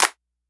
SBV_V12_Clap_008.wav